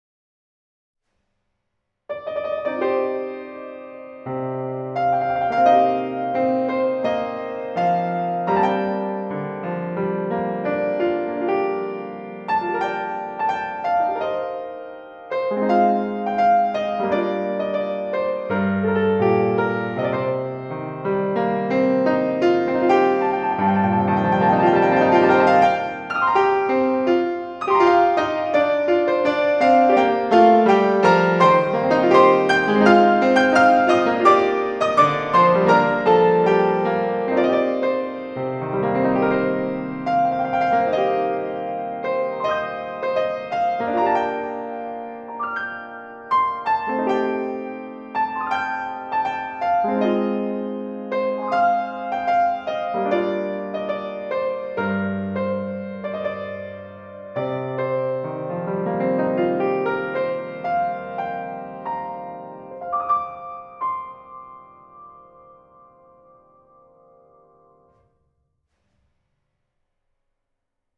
有点民族风格，但是猜不出题目来:)
有点多利亚调式的味道。
别扯教会调式，我这就是雅乐C羽，完全中国风格，把外国歌曲变成中国民乐合奏的感觉。